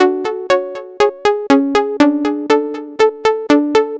TSNRG2 Lead 019.wav